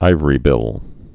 (īvə-rē-bĭl, īvrē-)